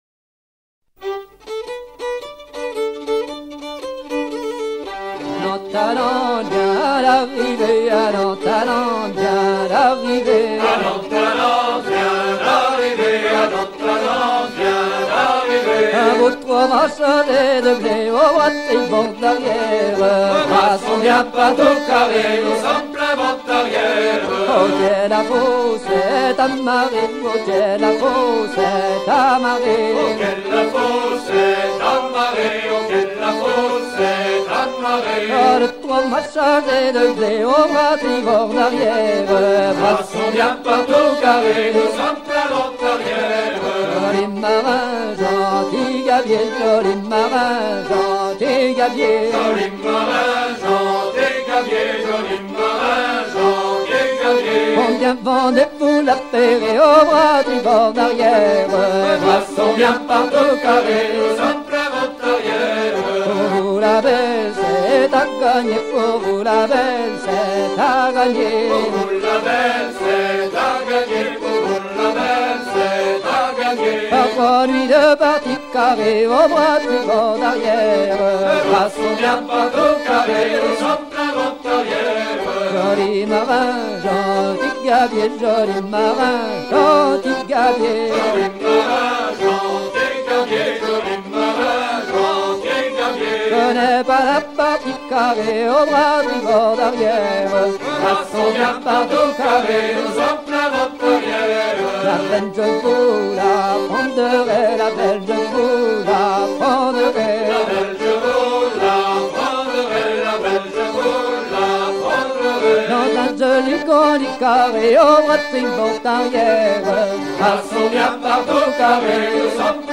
Fonction d'après l'analyste gestuel : à virer au cabestan ;
Genre laisse
Pièce musicale éditée